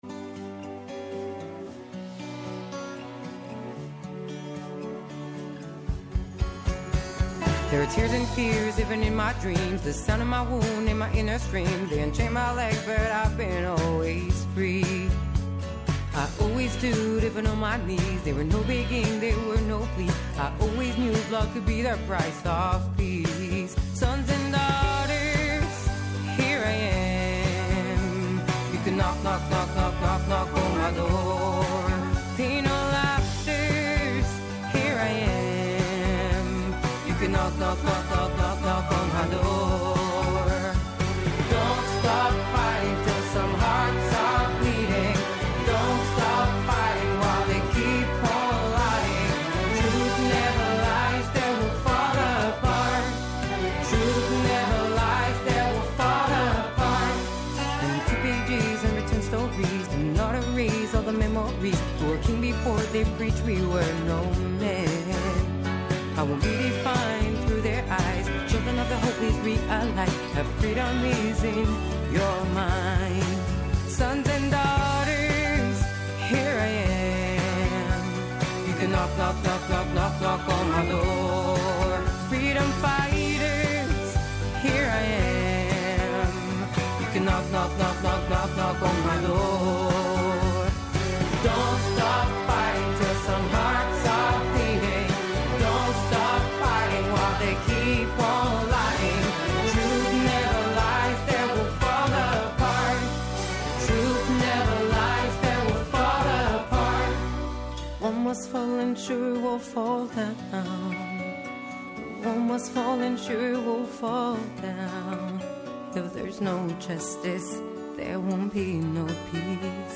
-ο Γιώργος Φάβας , Αντιδήμαρχος Καλαμάτας
Κάθε Παρασκευή 10 με 11 το πρωί και κάθε Σάββατο 10 το πρωί με 12 το μεσημέρι στο Πρώτο Πρόγραμμα της Ελληνικής Ραδιοφωνίας.